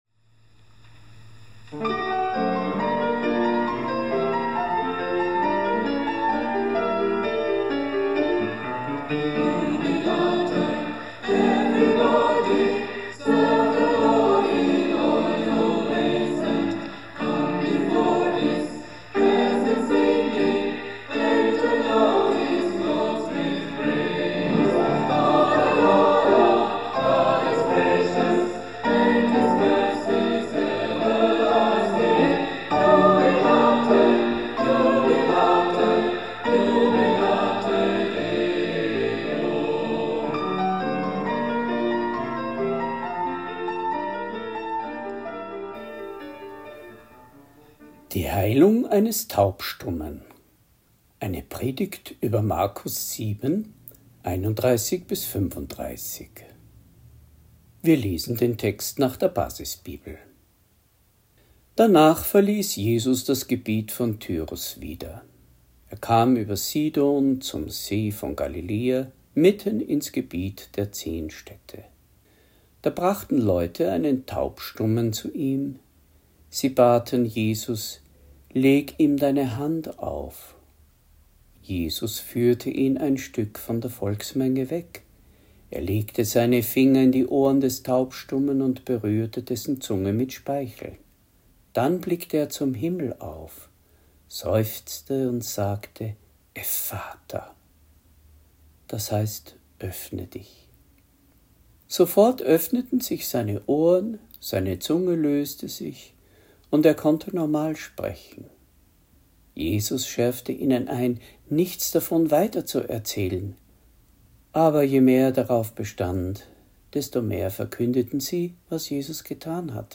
Predigt | NT02 Markus 7,31-35 Die Heilung eines Taubstummen